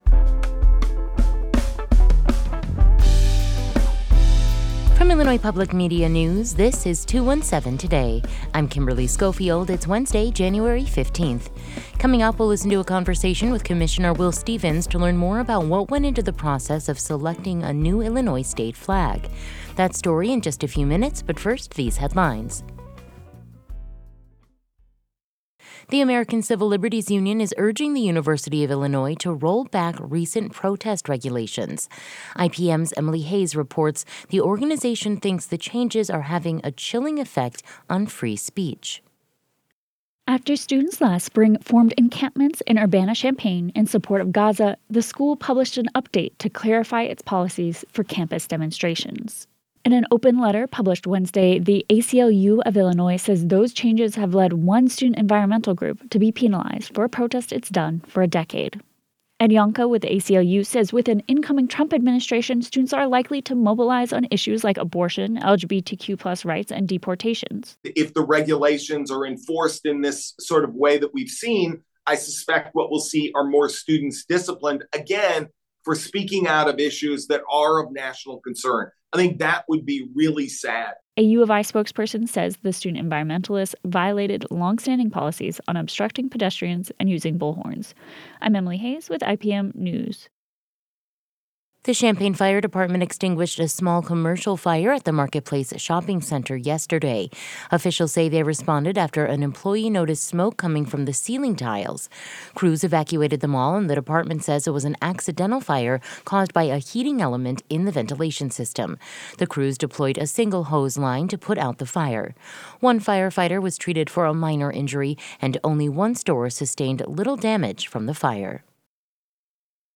Today’s headlines: